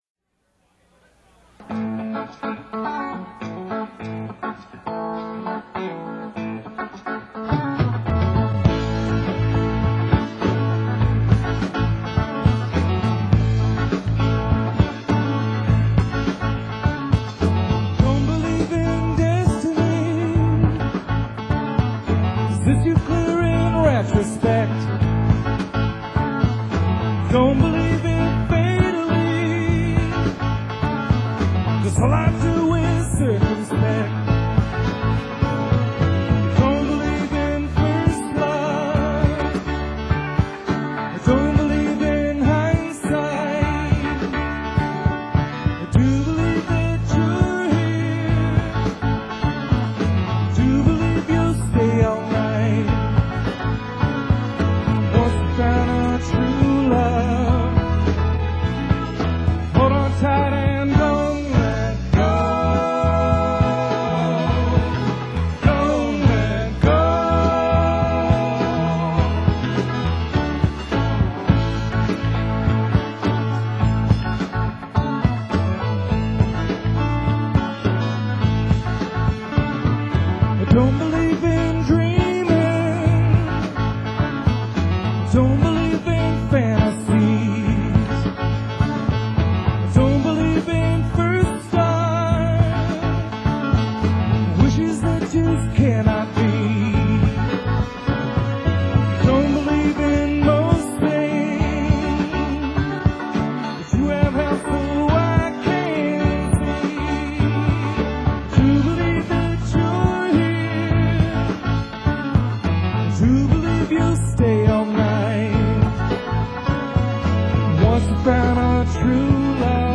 drums
guitar & vocals
bass & vocals